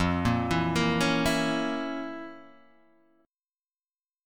F Major 7th Suspended 4th Sharp 5th